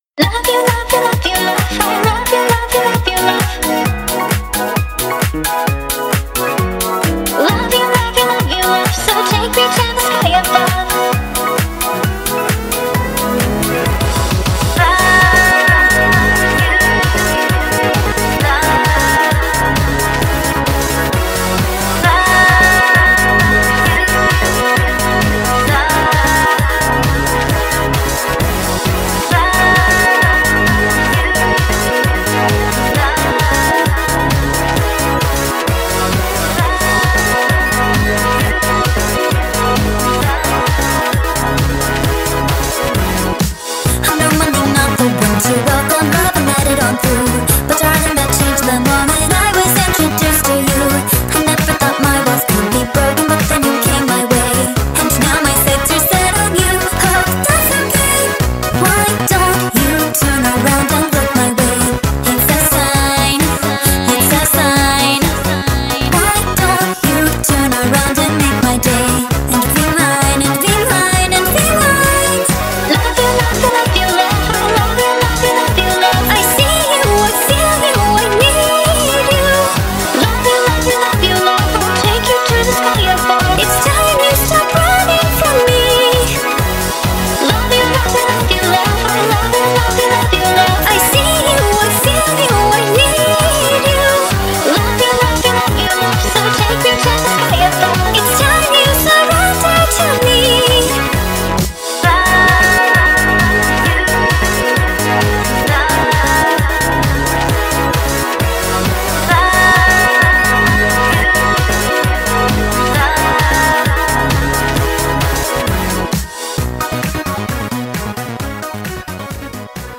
BPM132
Comments[SWITCH FORCE NU-DISCO]